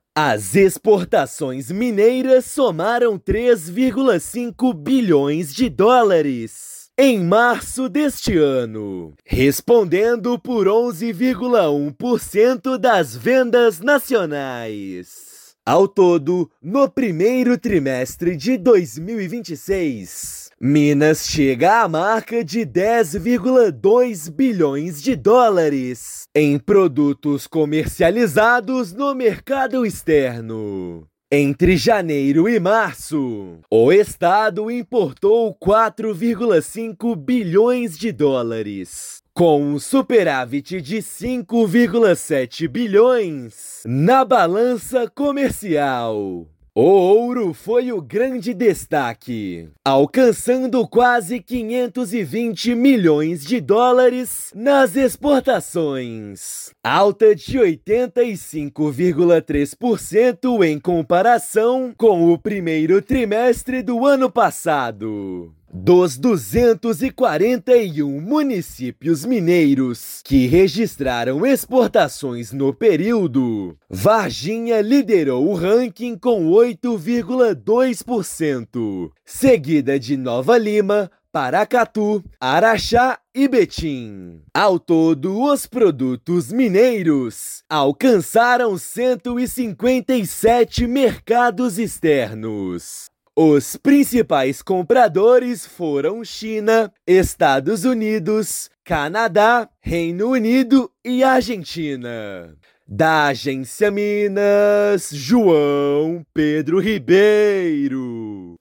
Desempenho do café, minérios de ferro, ouro, soja e ferro-ligas são destaques do período. Ouça matéria de rádio.